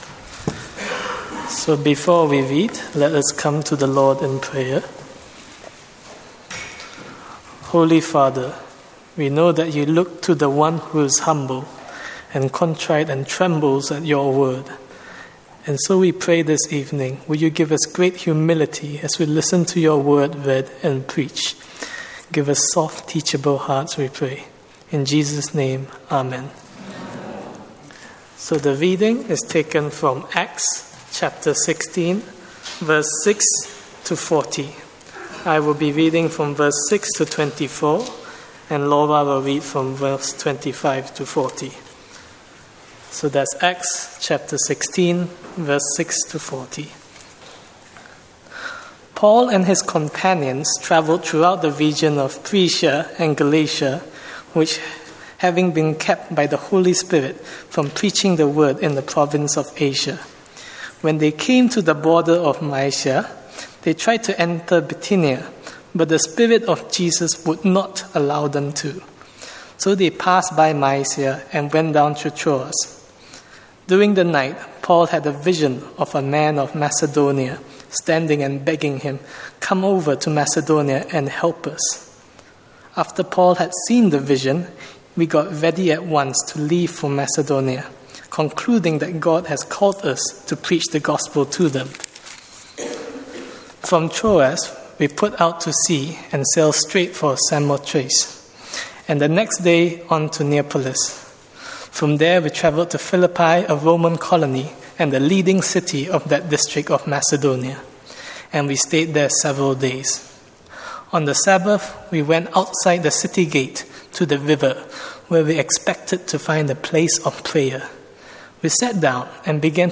Sermons Archive - Page 81 of 188 - All Saints Preston